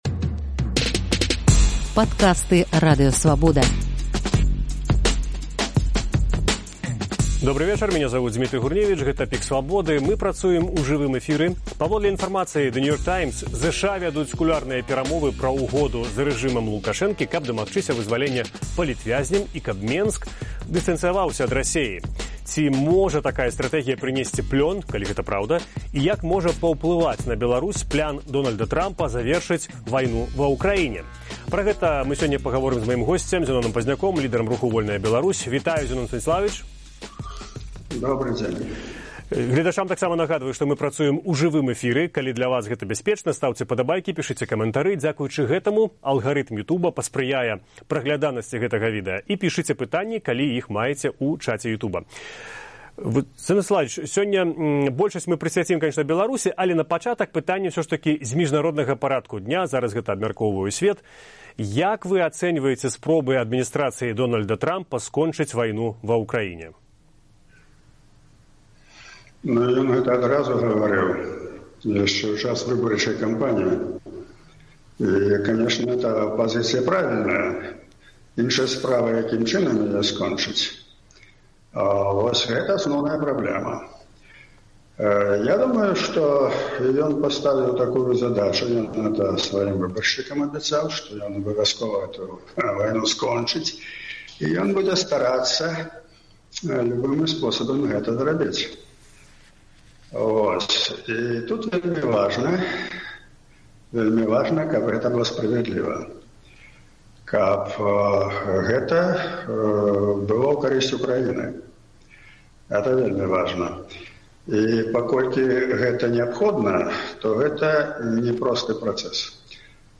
Лідэр руху «Вольная Беларусь» Зянон Пазьняк у жывым эфіры Свабоды